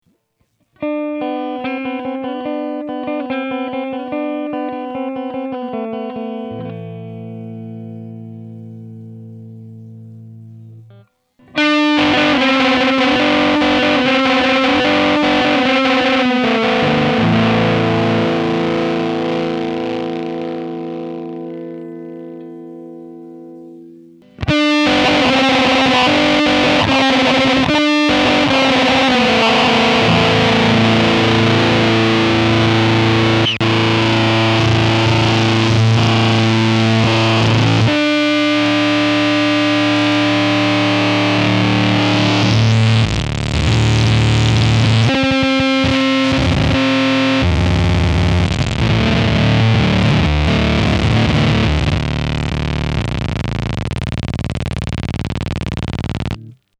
The added gain changes a few things about the PU: In "normal" mode, it's more of a fuzzbox, in "starve" mode, there's less gating, and in "osc" mode, there's more control over whether or not it's oscillating.
apuguitar.mp3